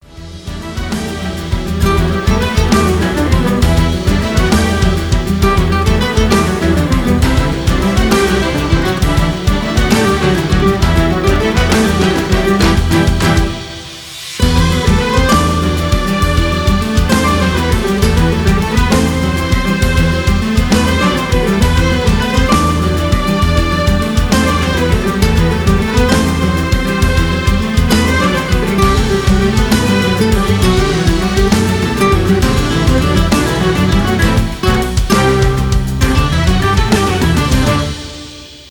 без слов
инструментальные